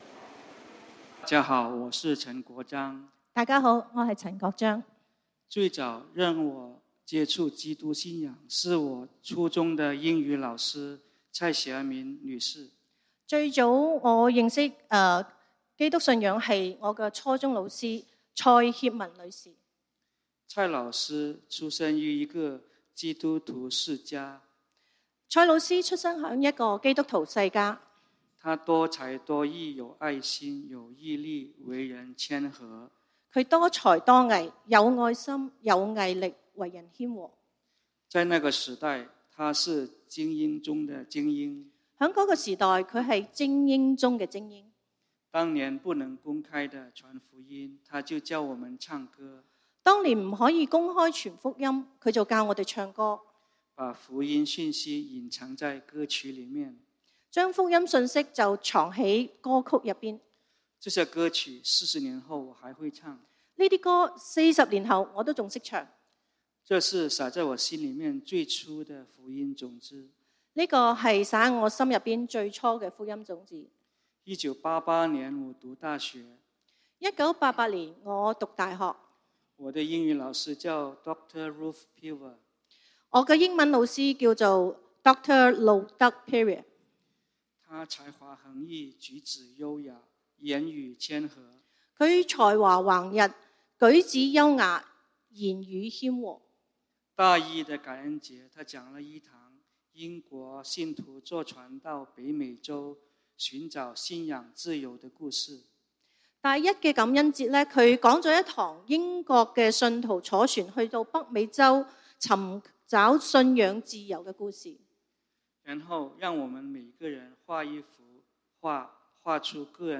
福音主日 講道經文：《約翰福音》John 14:27